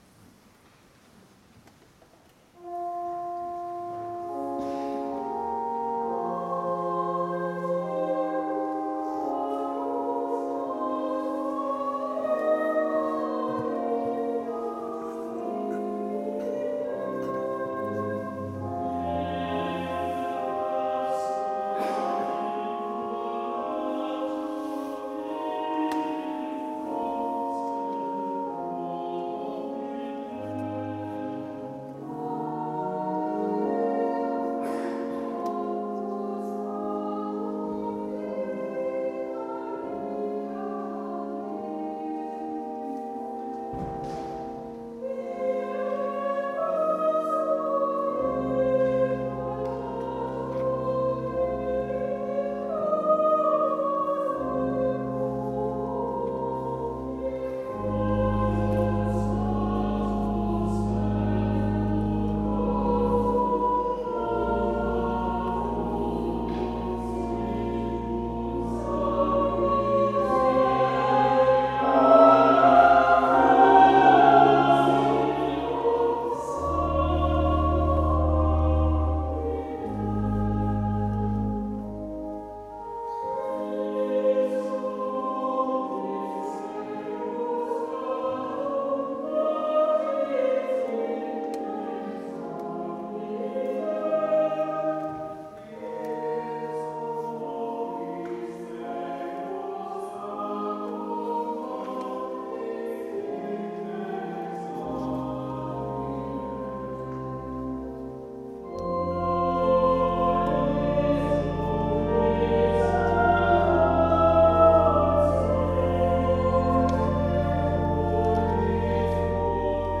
2024 trat Incantiamo im Passionskonzert zusammen mit dem Bach-Chor auf und ließ Kostbarkeiten von Bruckner und Fauré erklingen.